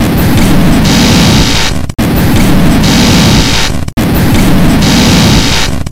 GlitchTowerSound.mp3